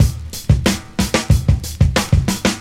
• 92 Bpm Breakbeat B Key.wav
Free drum loop sample - kick tuned to the B note. Loudest frequency: 1404Hz
92-bpm-breakbeat-b-key-qhg.wav